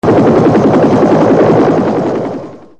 Suono effetto Elicottero wav sample loop
Elicottero
Rumore di elicottero in avvicinamento da loopare.
HELICPTR.mp3